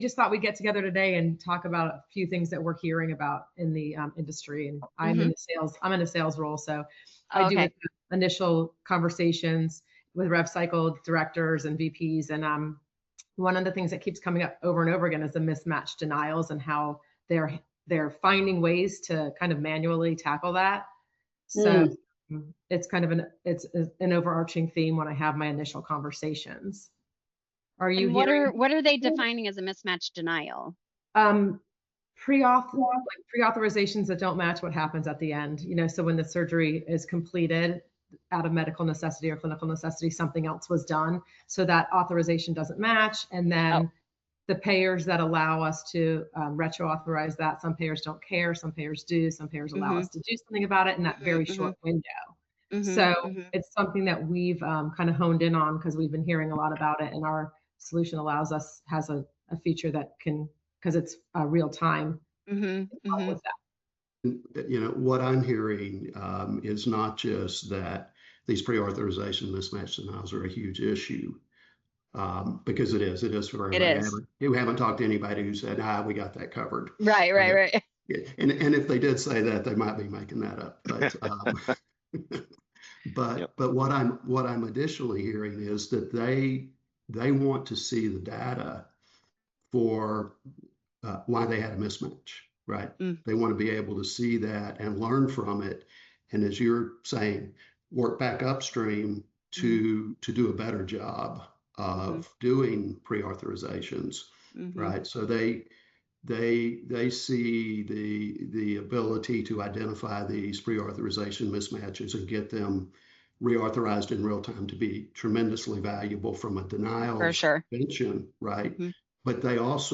Just a conversation.